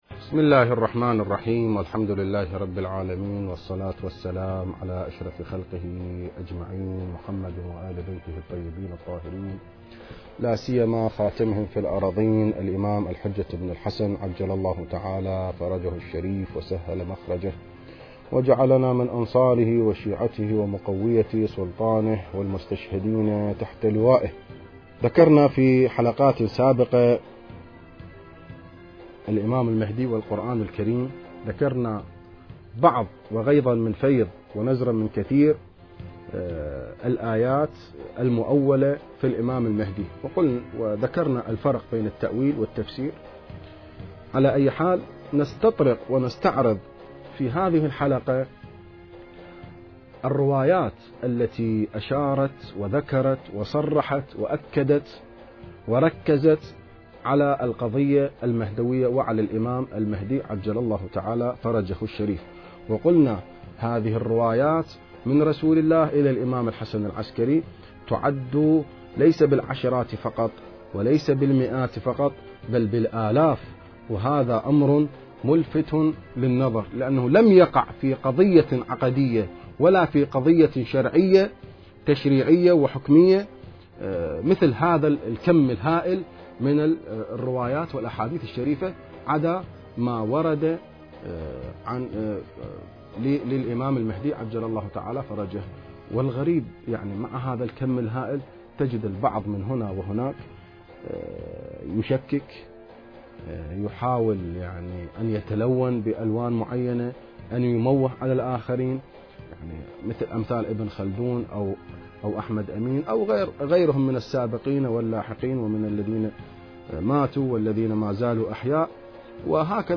المكان: اذاعة الفرات